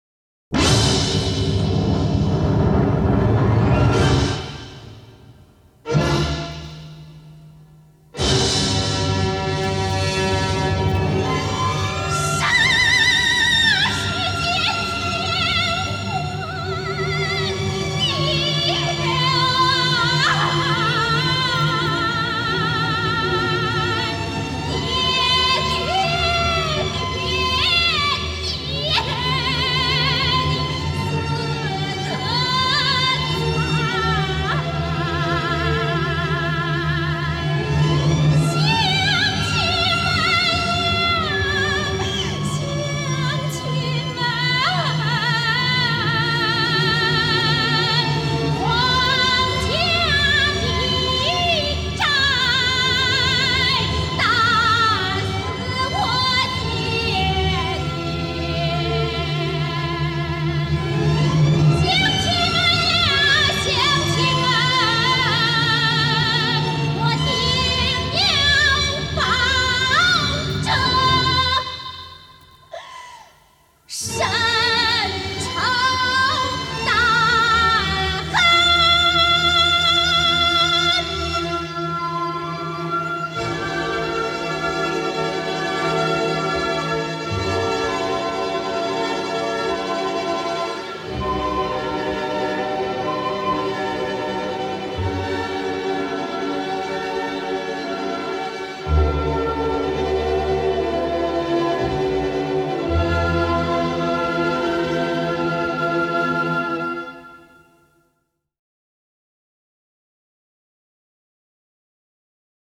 尤其是那一开嗓就撕心裂肺出的a2音，是很多咏叹调唱段都不能及的。